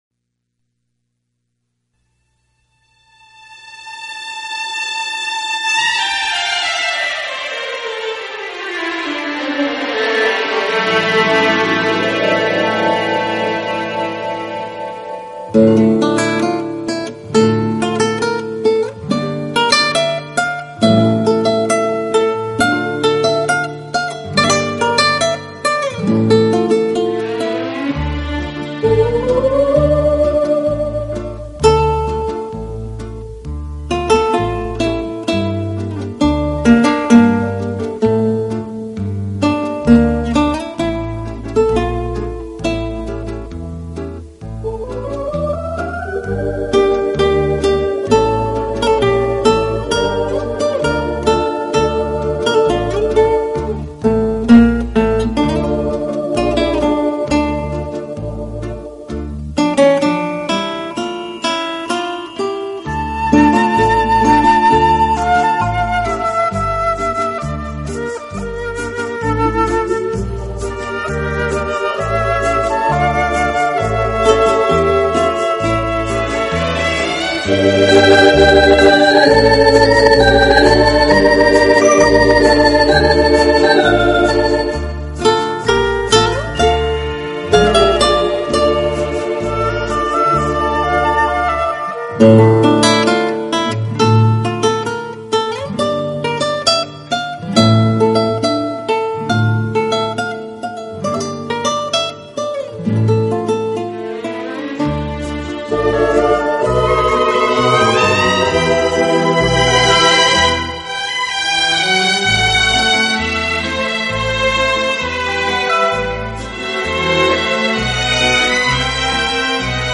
专辑音色清脆动人且温馨旖丽，不禁展示了精彩绝伦的空间感，而且带出吉他音箱共鸣声